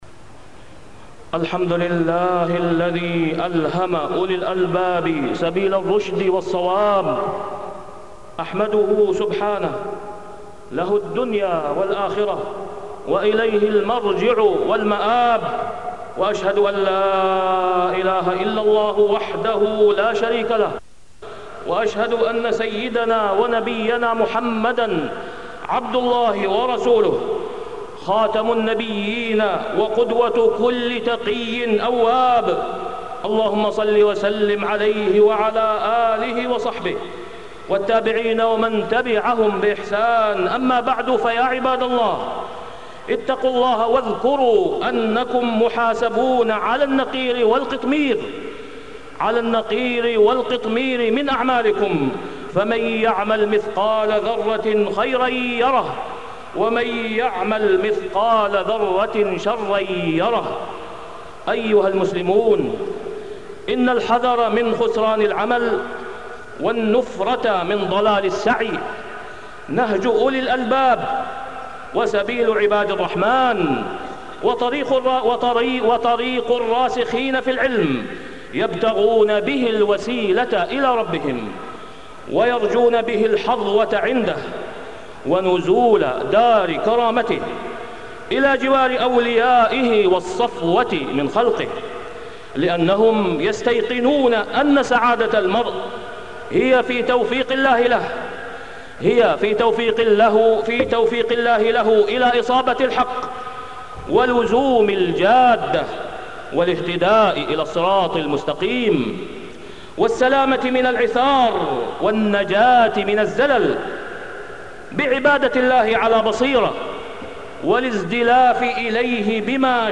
تاريخ النشر ٢٨ شوال ١٤٢٥ هـ المكان: المسجد الحرام الشيخ: فضيلة الشيخ د. أسامة بن عبدالله خياط فضيلة الشيخ د. أسامة بن عبدالله خياط الأخسرون أعمالا